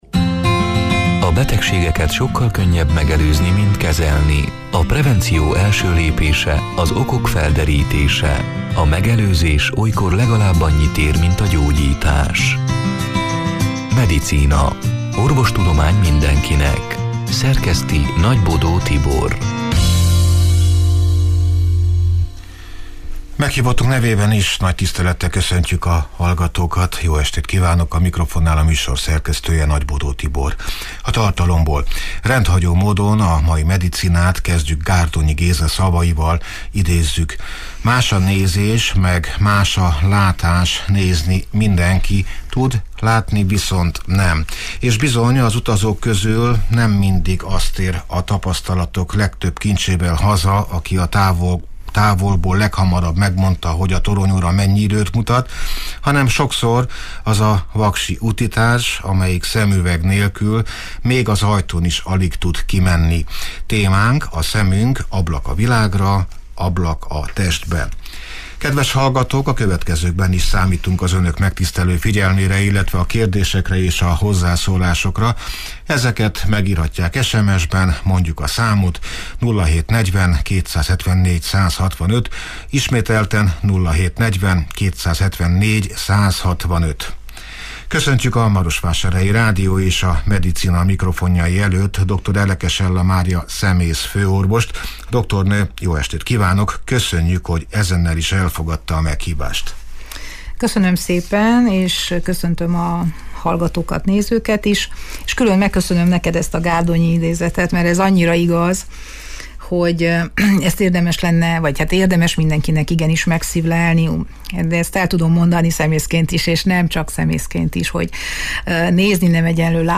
(elhangzott: 2023. október 25-én, szerdán este nyolc órától élőben)